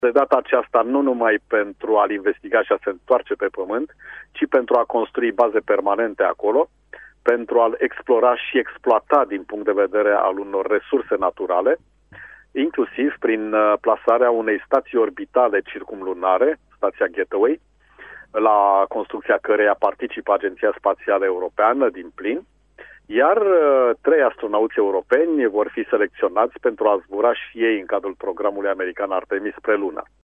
Cosmonautul Dumitru Prunariu, invitat joi în emisiunile Radio Timișoara, e convins că succesul unei noi aselenizări va fi pasul dinaintea unei expediții și mai complexe spre planeta Marte.